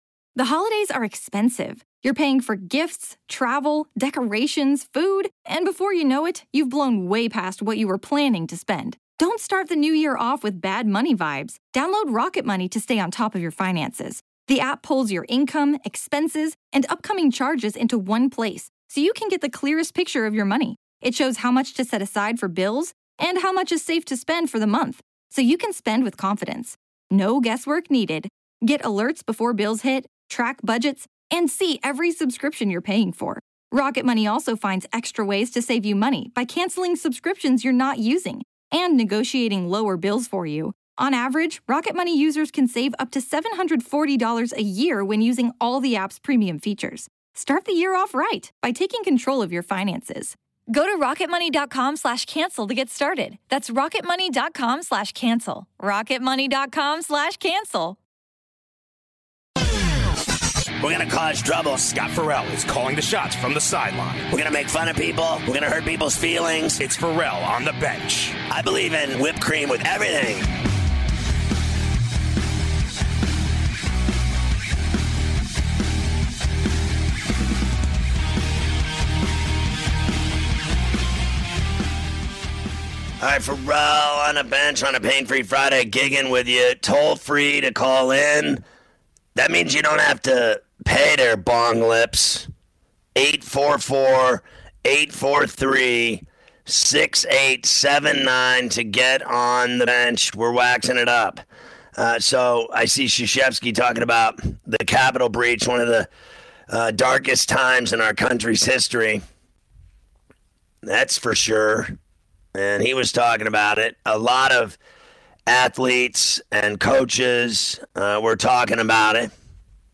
Scott Ferrall talks about the NFL Playoff Games this weekend and listens to sound from some of the players and coaches involved